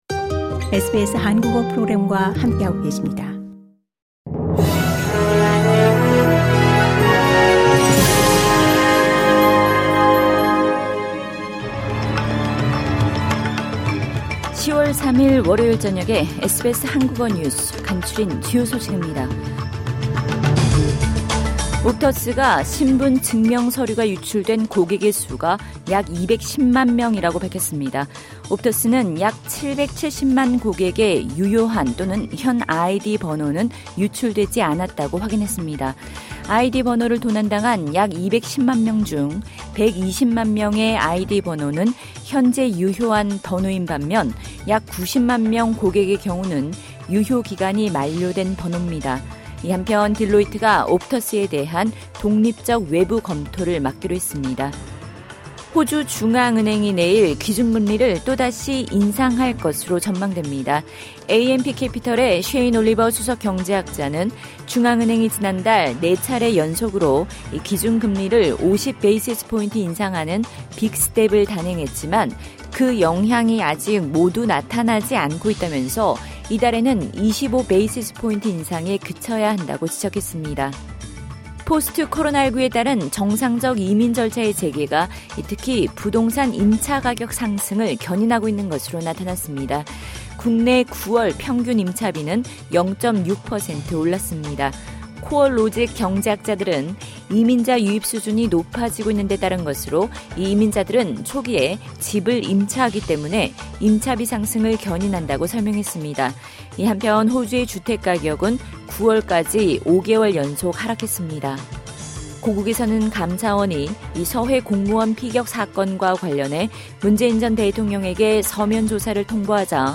2022년 10월 3일 월요일 저녁 SBS 한국어 간추린 주요 뉴스입니다.